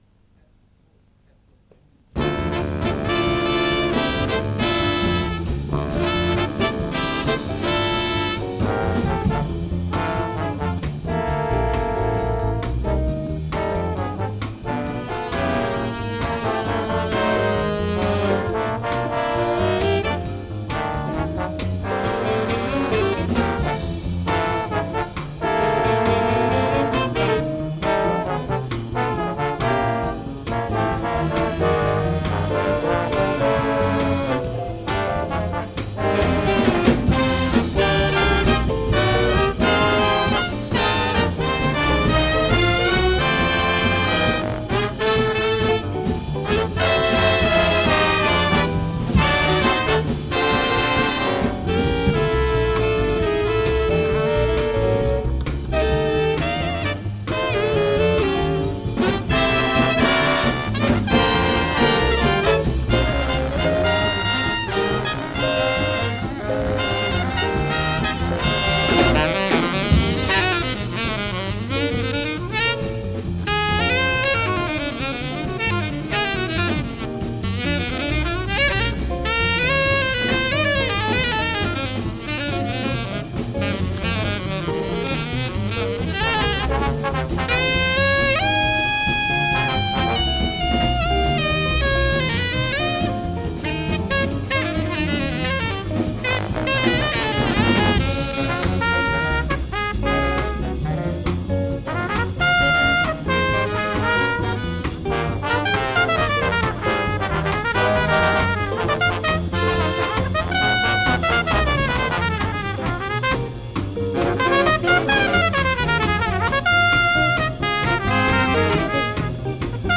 storband som spelar 40-50-talets legendariska jazzmusik
Saxofoner:
Trumpeter:
Tromboner:
Rytmsektion: